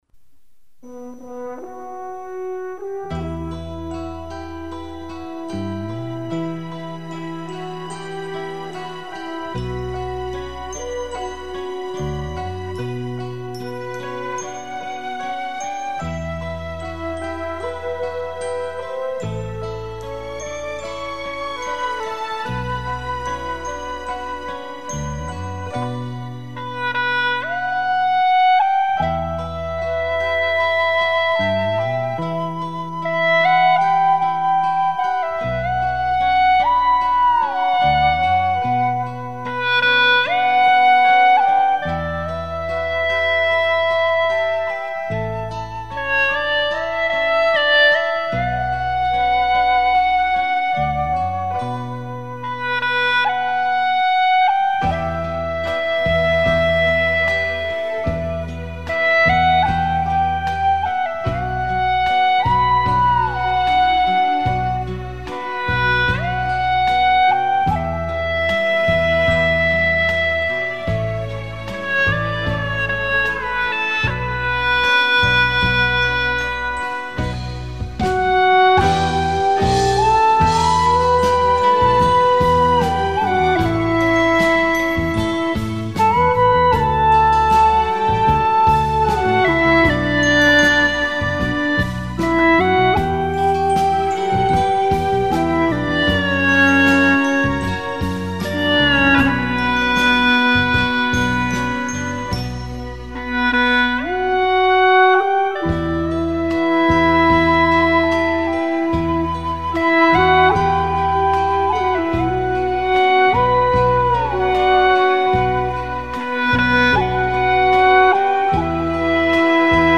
调式 : D 曲类 : 流行
缓缓的节奏、简单的旋律流淌出淡淡的忧伤